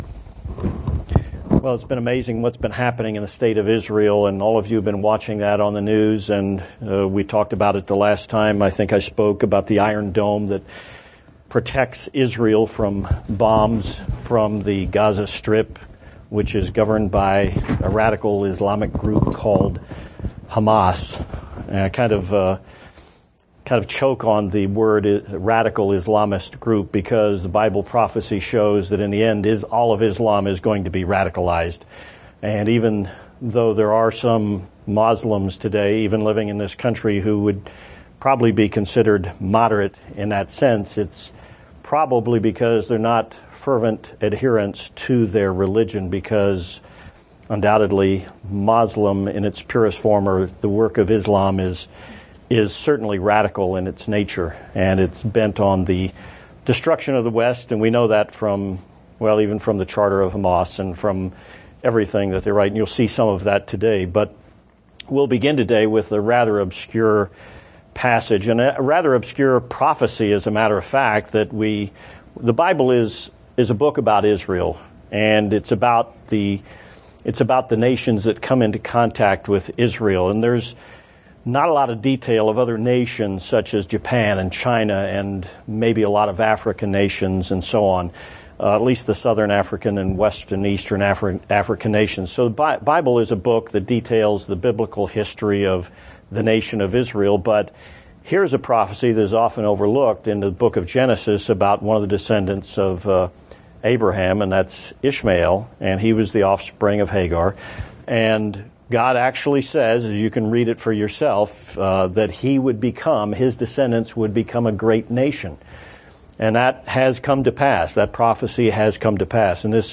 Given in El Paso, TX
UCG Sermon Studying the bible?